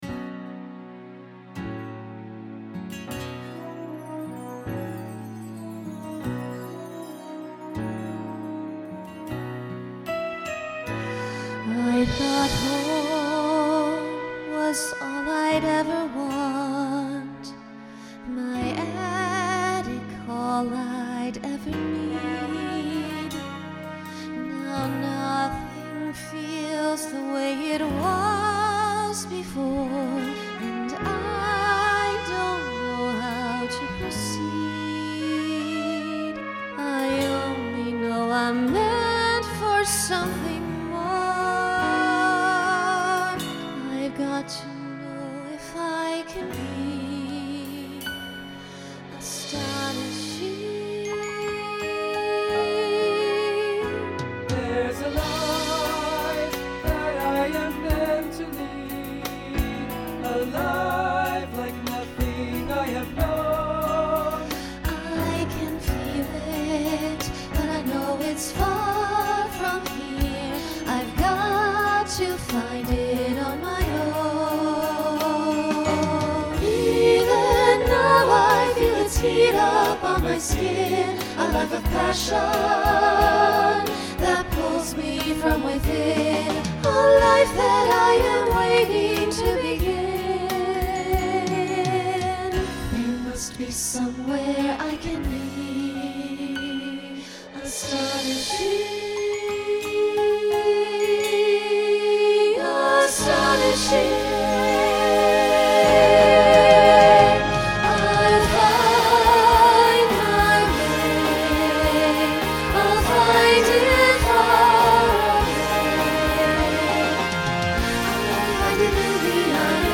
SATB Instrumental combo
Broadway/Film
Ballad